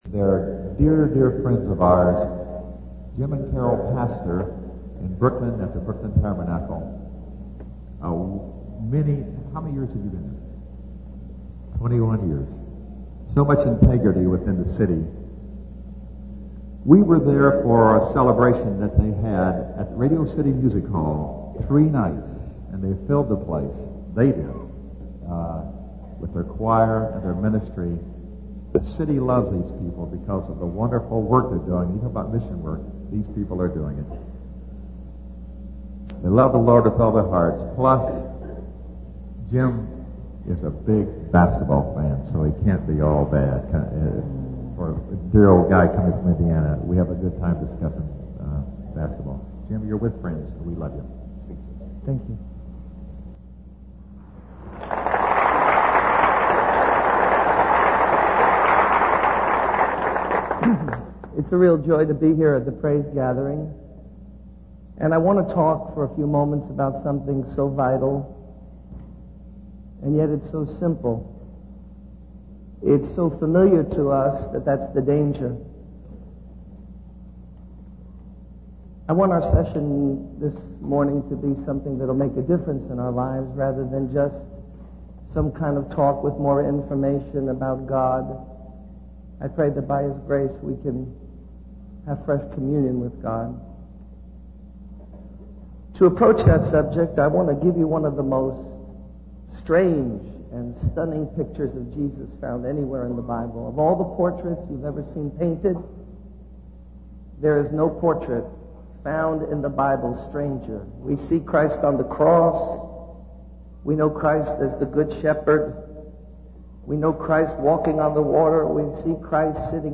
In this sermon, the speaker reflects on the story of Jesus overturning tables in the temple. He emphasizes the importance of not only doing God's work, but also doing it in the right spirit.